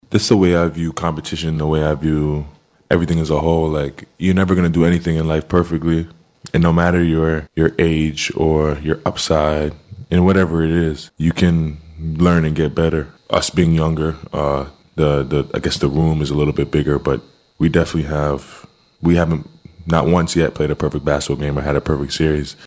Superstar Shai Gilgeous-Alexander says t